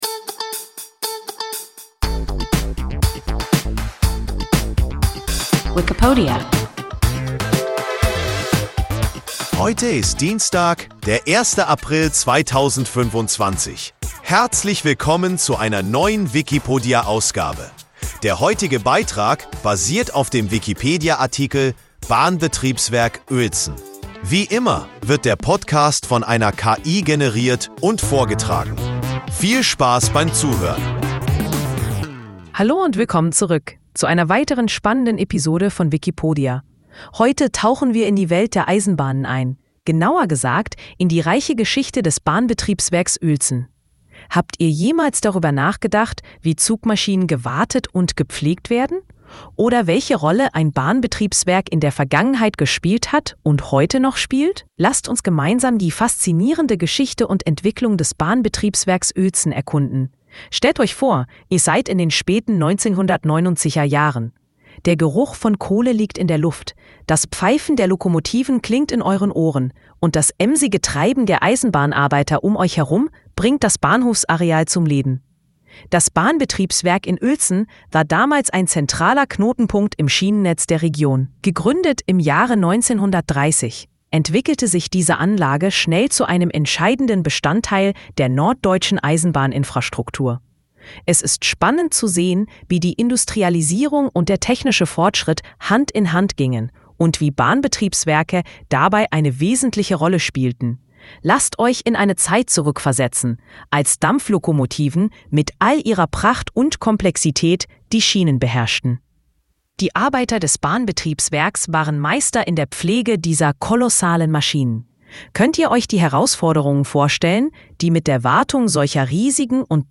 Bahnbetriebswerk Uelzen – WIKIPODIA – ein KI Podcast